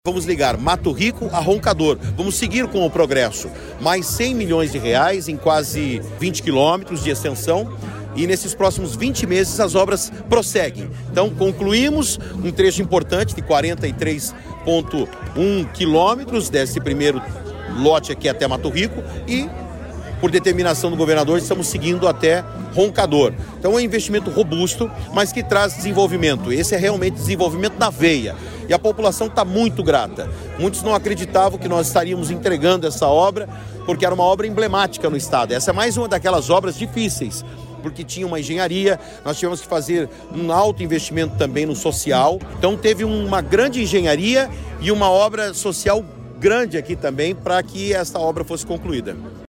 Sonora do secretário de Infraestrutura e Logística, Sandro Alex, sobre o início das obras da ligação asfáltica da PR-239 entre Mato Rico e Roncador